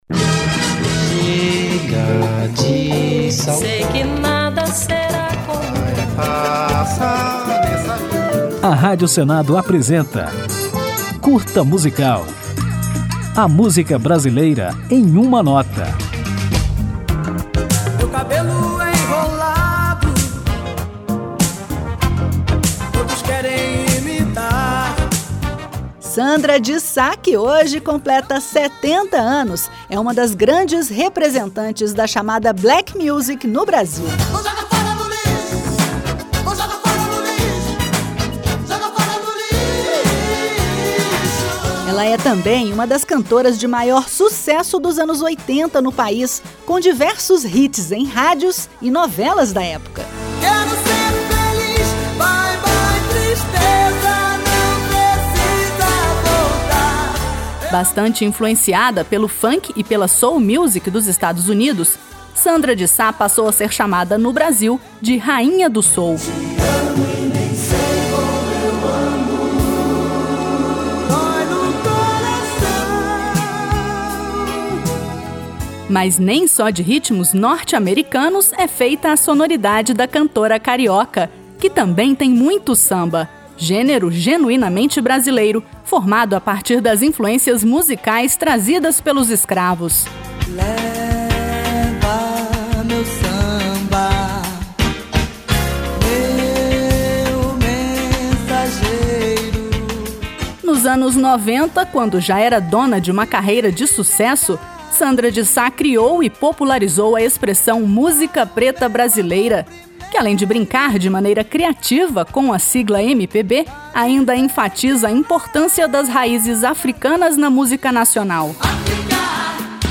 Depois de conferir um pouco da trajetória de Sandra de Sá, vamos ouvi-la no sucesso Olhos Coloridos.